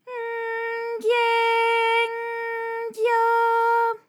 ALYS-DB-001-JPN - First Japanese UTAU vocal library of ALYS.
gy_N_gye_N_gyo.wav